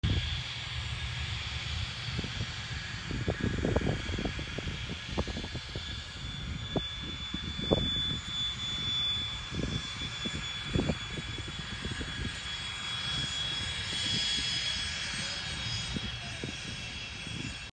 Maintenance runs on the Dassault sound effects free download